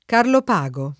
[ karlop #g o ]